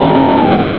sovereignx/sound/direct_sound_samples/cries/whiscash.aif at master